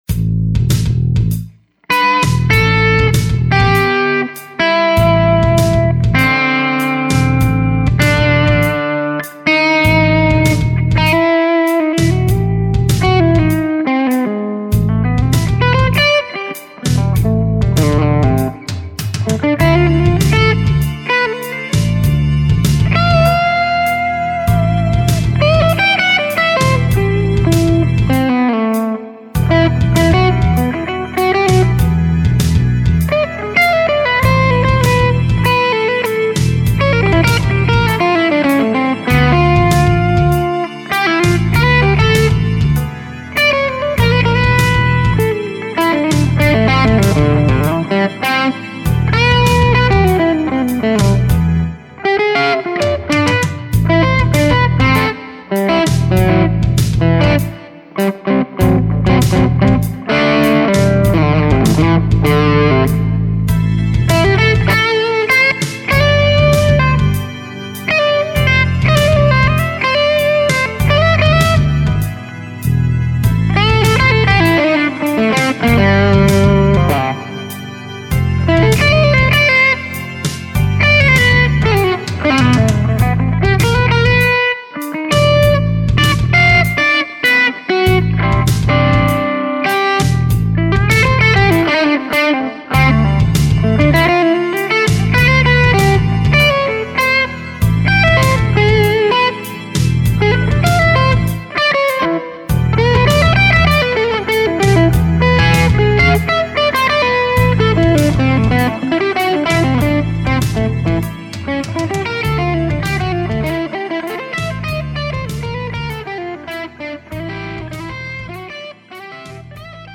All clips were Eminence RWB speaker and Audix I5 mic.